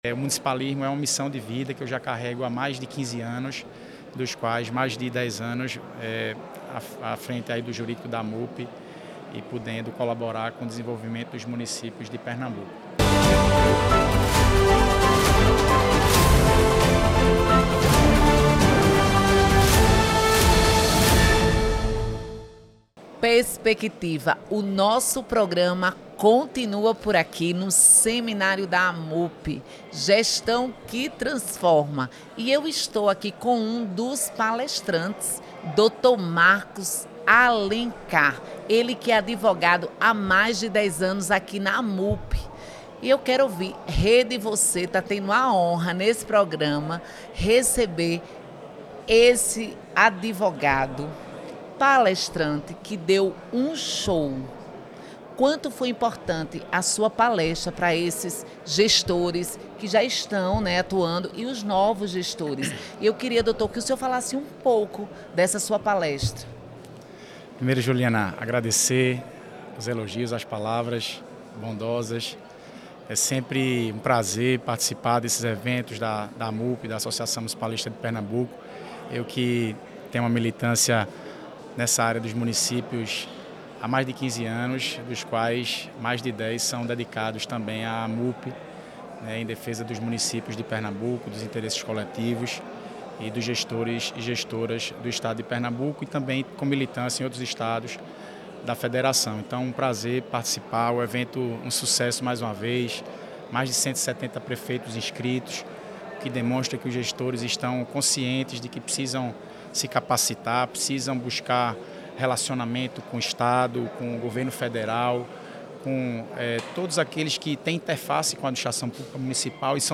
Em entrevista à Rede Você, o advogado destacou a importância de a gestão municipal estar preparada para atender adequadamente as comunidades.